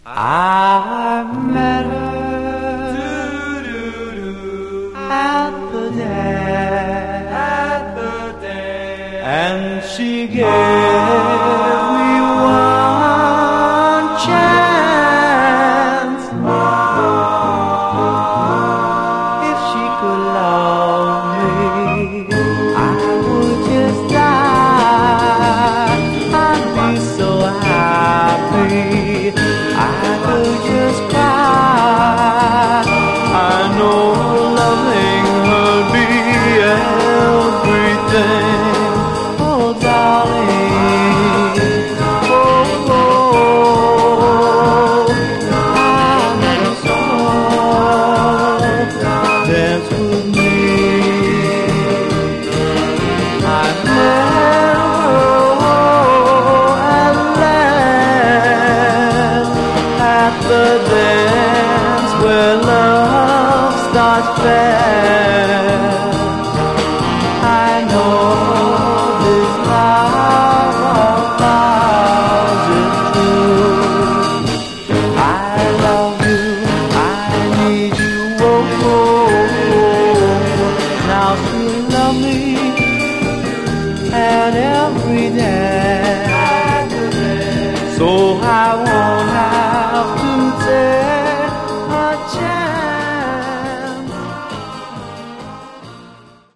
Genre: Vocal Groups (Doo-Wop)
This rare 1959 Doo Wop single has two fine uptempo sides